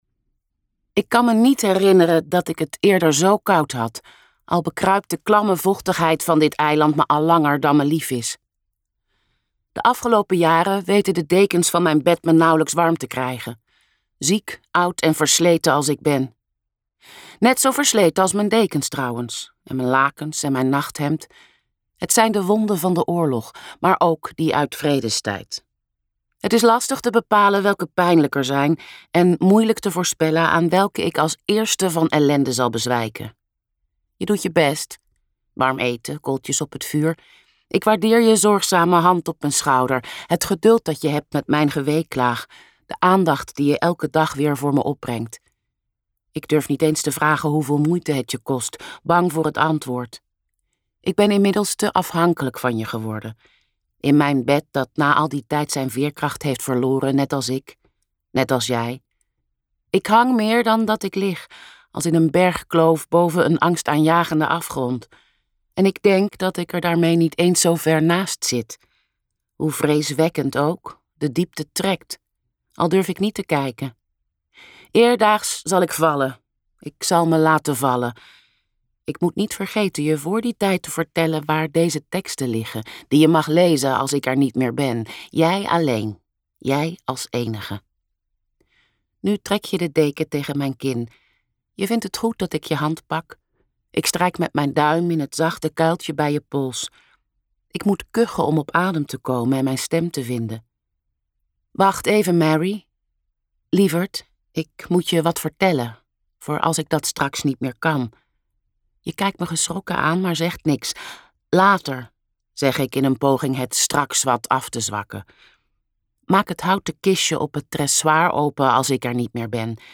Ambo|Anthos uitgevers - En garde luisterboek